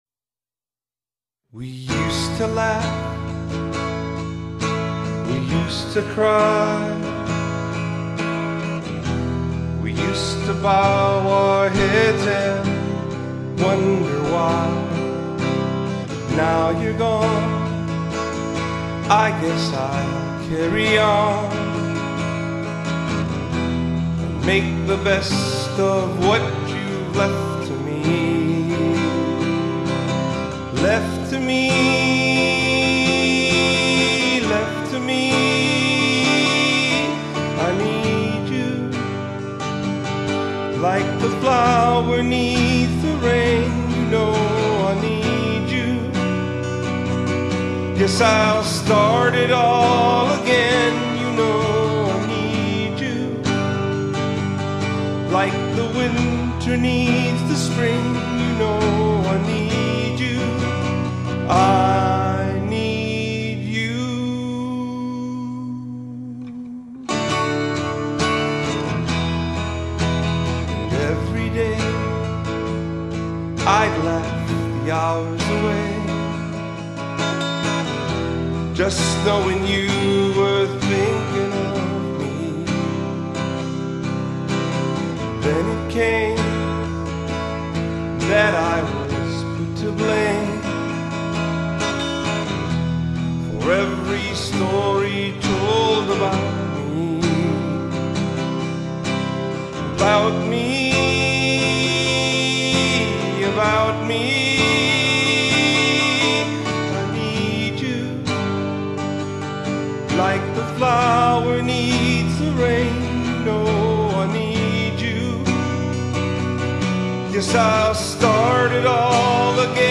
musical duo featuring vocalist/keyboardist